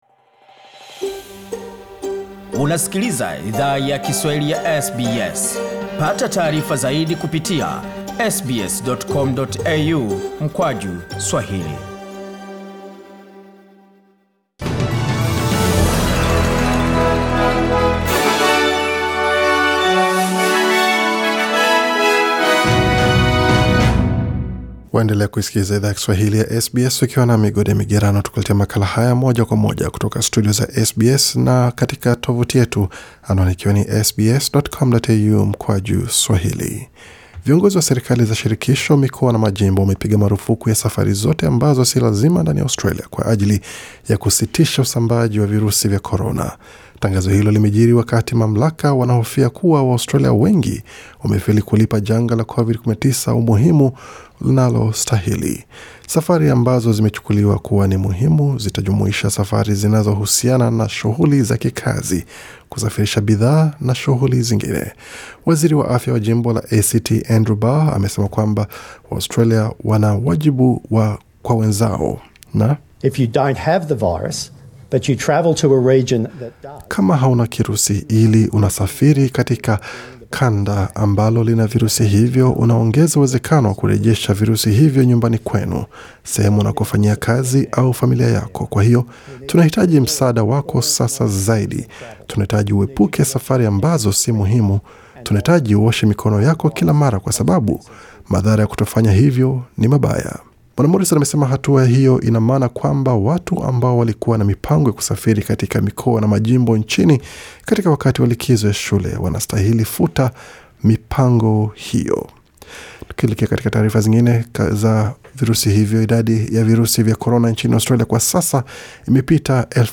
Taarifa za habari: Shule kuendelea kuwa wazi ila, sehemu za ibada na burudani kufungwa nchini